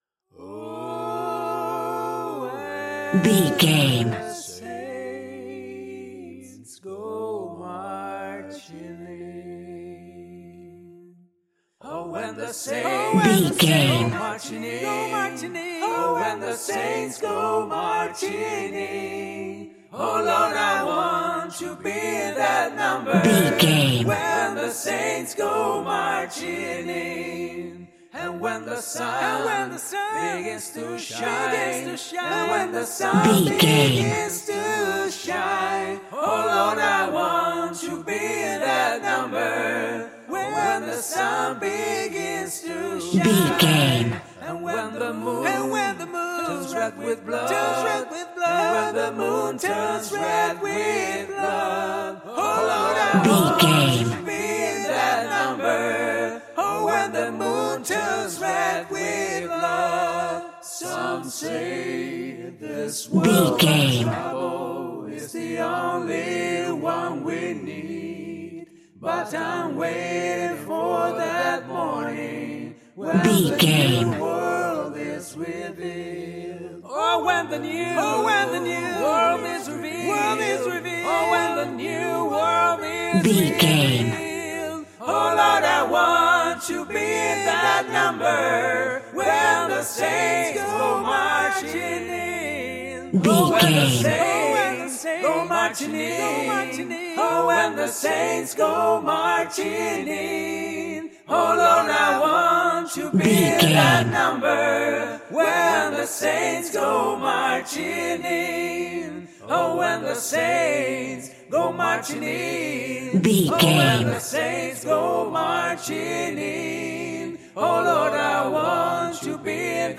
Aeolian/Minor
fun
groovy
inspirational